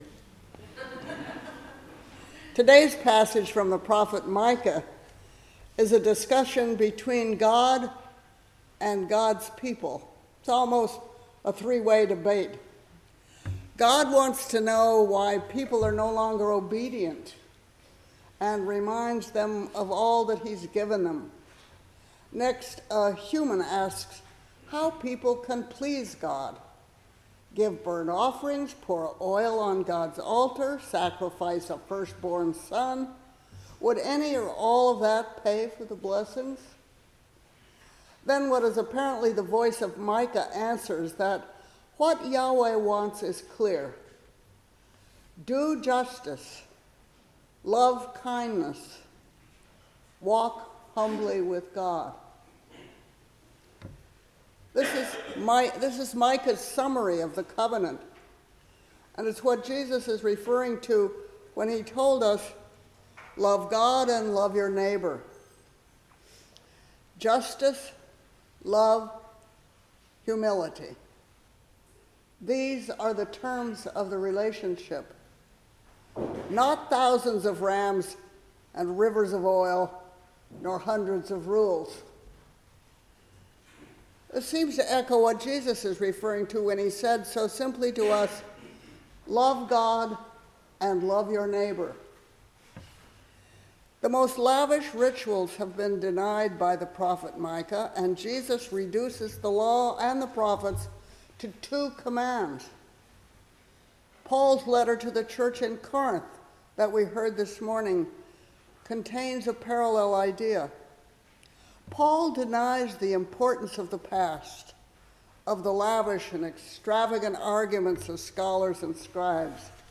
Passage: Micah 6:1–8, Psalm 15, 1 Corinthians 1:18–31, Matthew 5:1–12 Service Type: 10:00 am Service
Sermon-Fourth-Sunday-after-Epiphany-February-1-2026.mp3